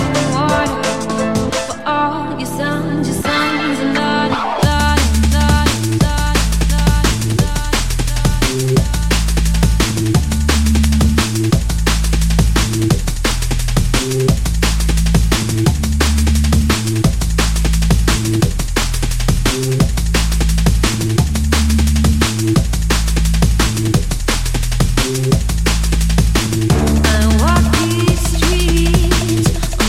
TOP >Vinyl >Drum & Bass / Jungle
TOP > Vocal Track
TOP > Deep / Liquid